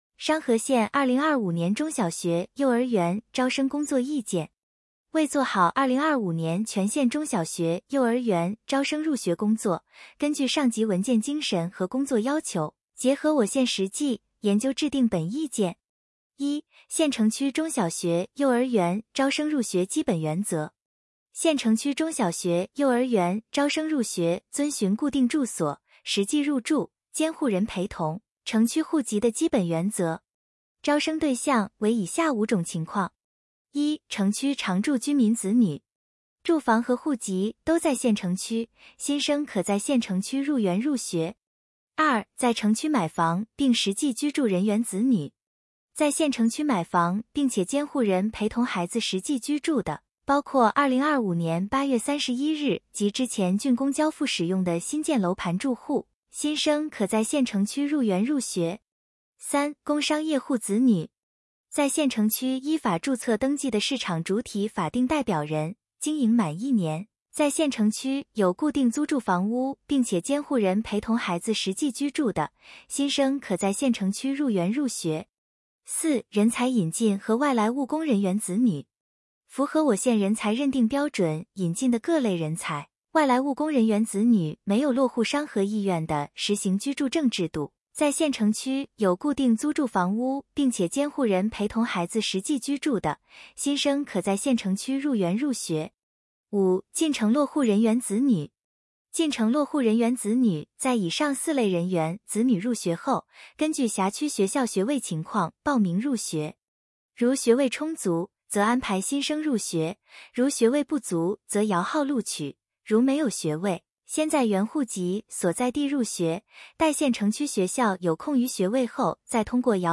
有声朗读：《商河县教育和体育局关于2025年中小学（幼儿园）招生工作意见的通知》